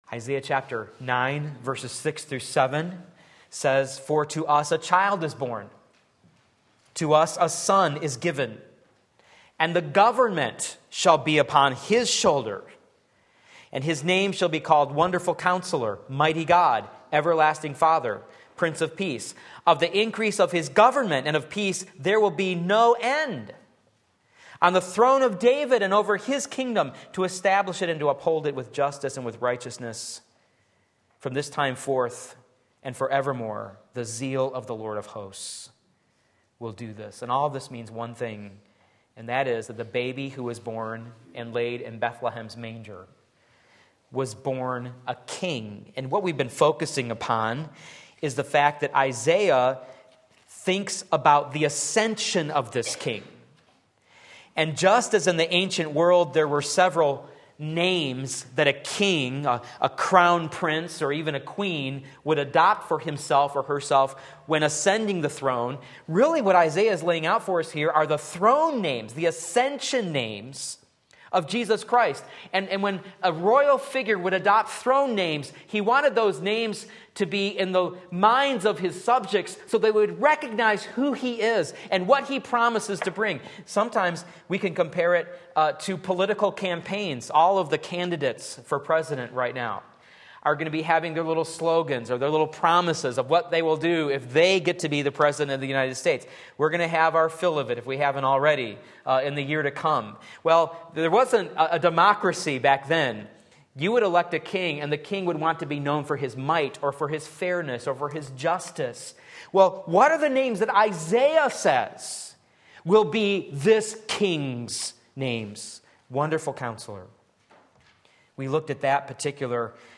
Sermon Link
Part 3 Isaiah 9:6 Sunday Morning Service